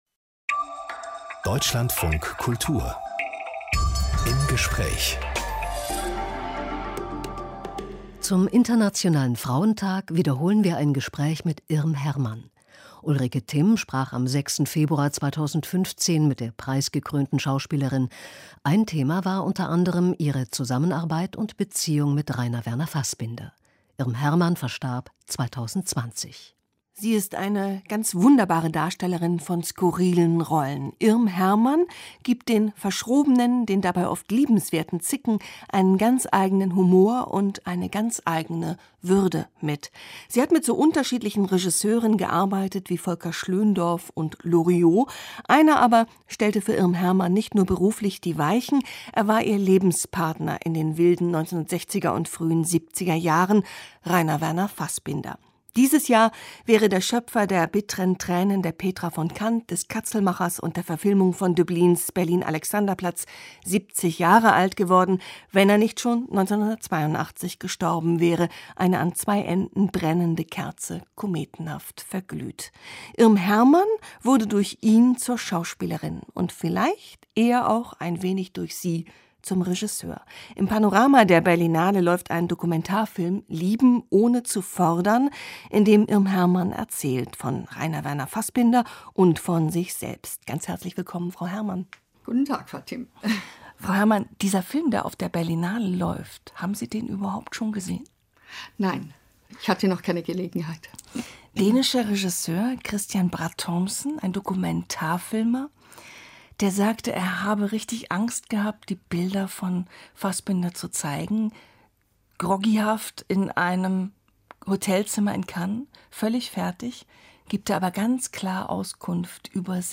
Zum Internationalen Frauentag wiederholen wir ein Gespräch mit der preisgekrönten Schauspielerin Irm Hermann.